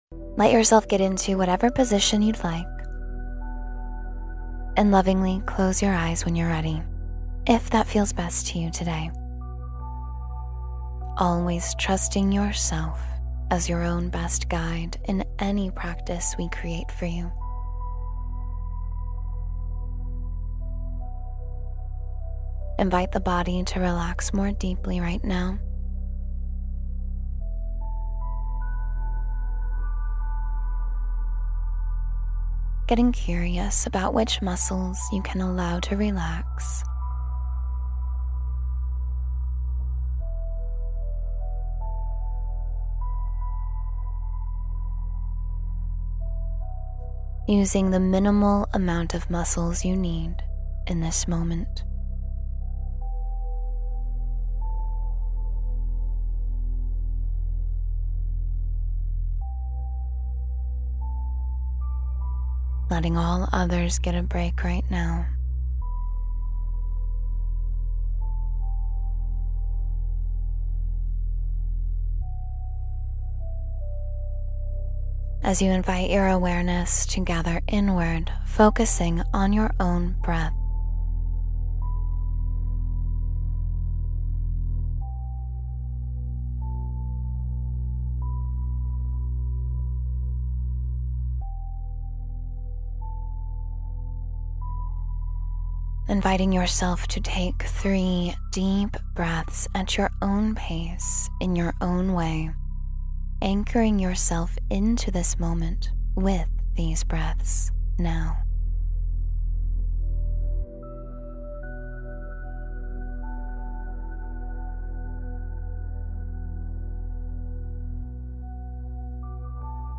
You Deserve Ease Wherever You Are — Meditation for Relaxation and Clarity